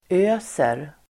Uttal: ['ö:ser]